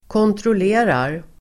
Uttal: [kåntrål'e:rar]